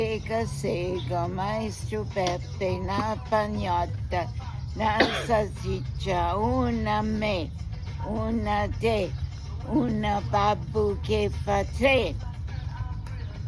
Cette sauteuse est en dialecte des Marches.